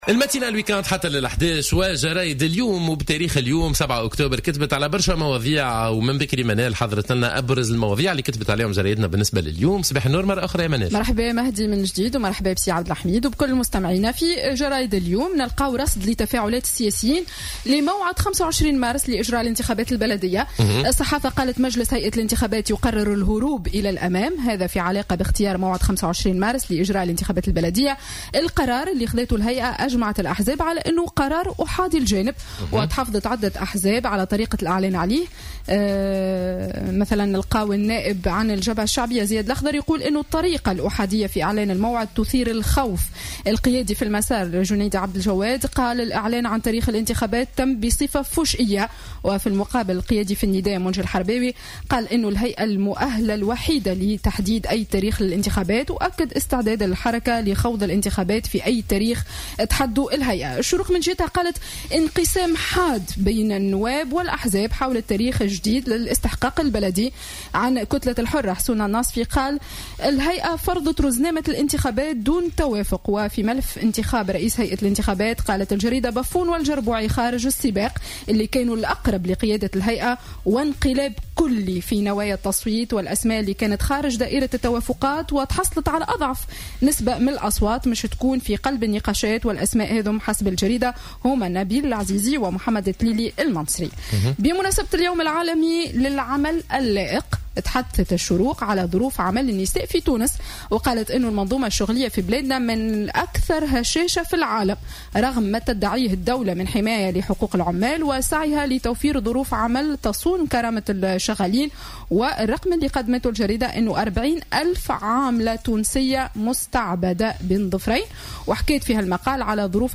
Revue de presse du samedi 7 octobre 2017